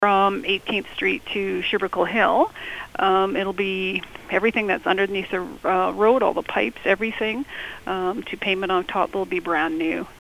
City councillor Sue Heaton-Sherstobitoff explains the work which will be taking place.